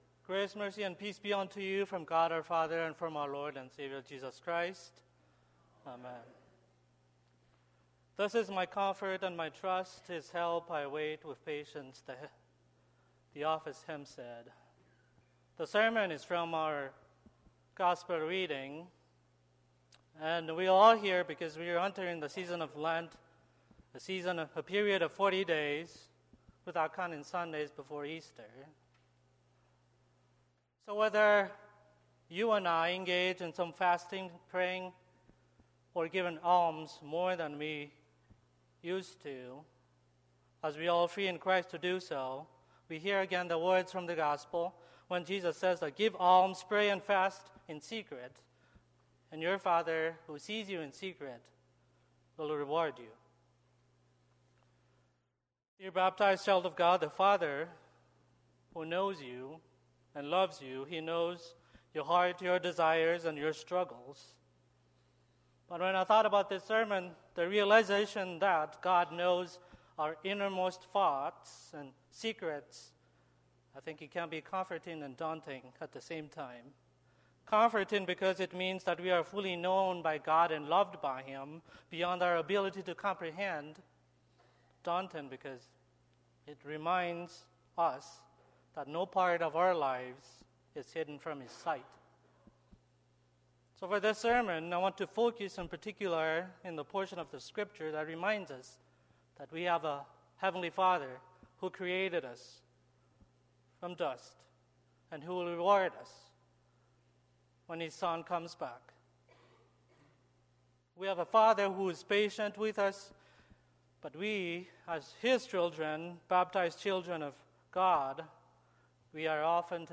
Sermon - 2/14/2024 - Wheat Ridge Lutheran Church, Wheat Ridge, Colorado
Ash Wednesday